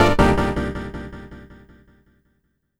12SFX 01  -L.wav